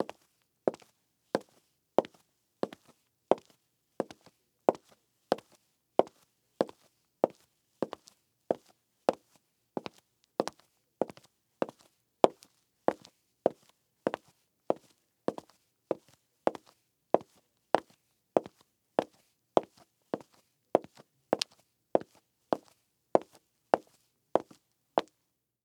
Footsteps